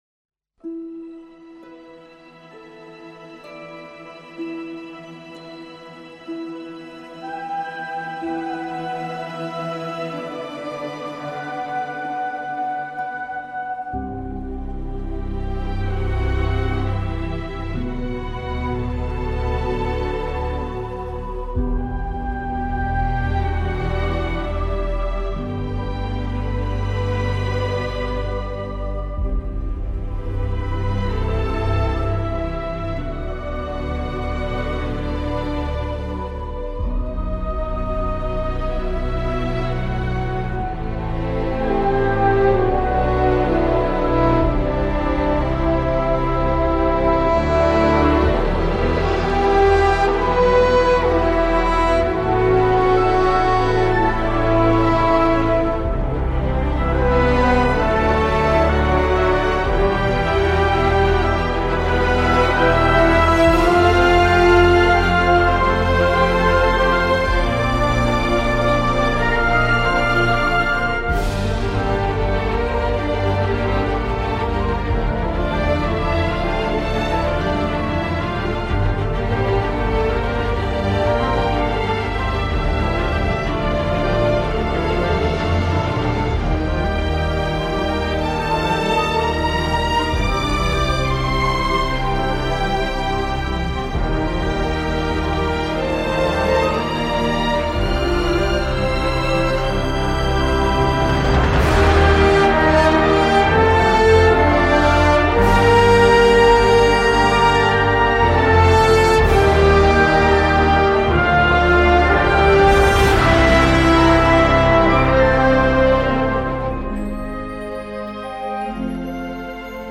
un peu d’électro de complément